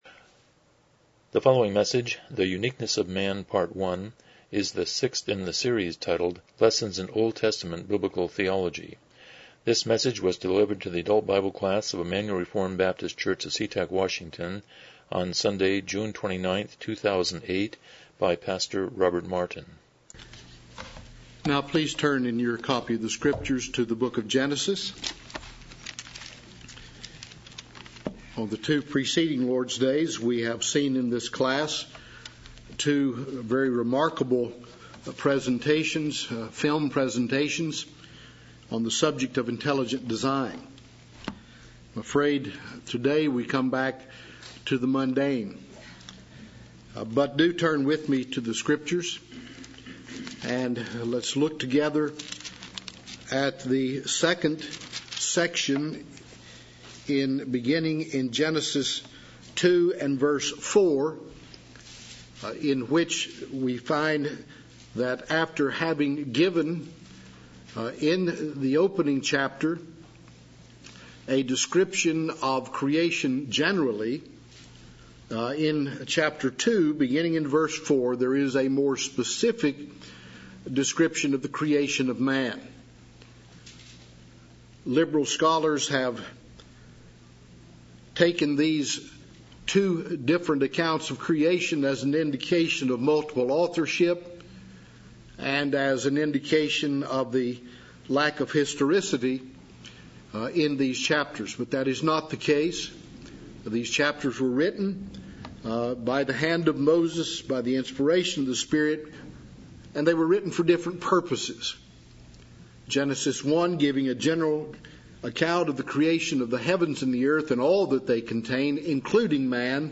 Lessons in OT Biblical Theology Service Type: Sunday School « 49 Review #7